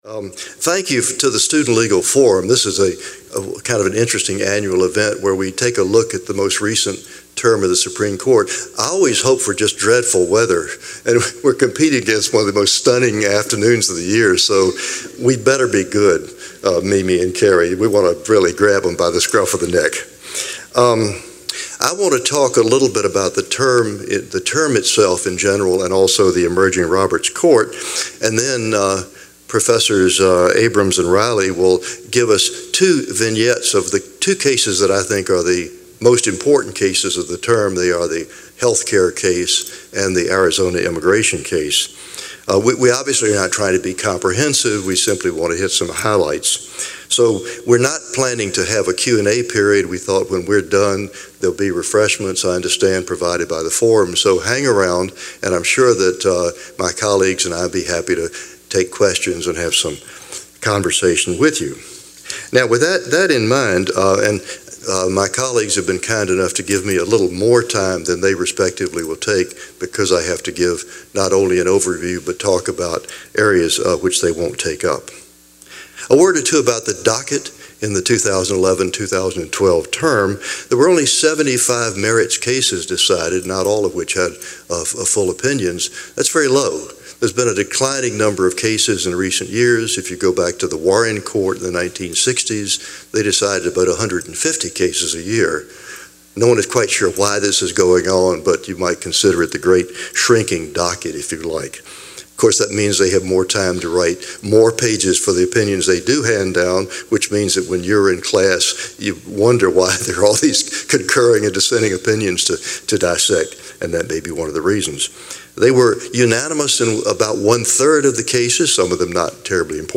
Before a packed crowd of students and faculty in Caplin Pavilion, a panel of three University of Virginia law professors explained the most important